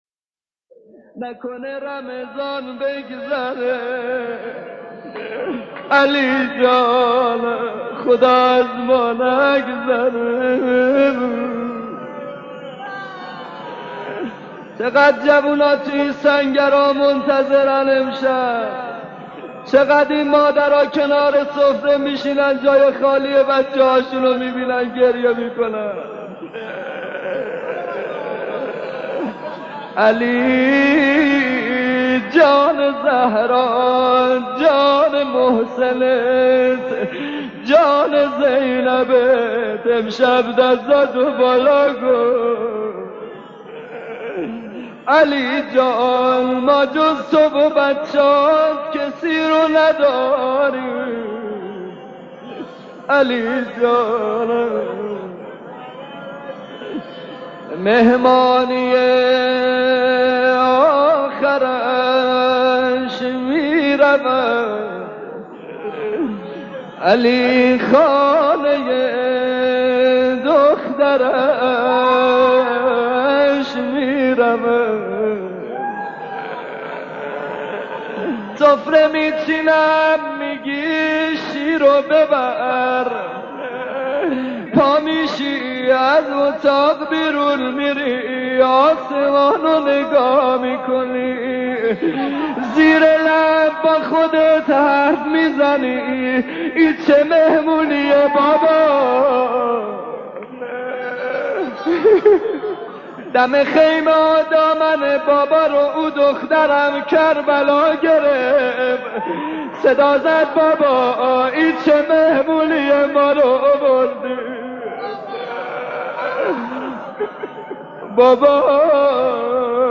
در پرده عشاق، صدای مداحان و مرثیه‌خوانان گذشته تهران قدیم را خواهید شنید که صدا و نفس‌شان شایسته ارتباط دادن مُحب و مَحبوب بوده است.
گریز از مصیبت‌خوانی شهادت امیرالمؤمنین (ع) به روز عاشورا و خواندن زیارت عاشورا